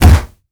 punch_heavy_huge_distorted_04.wav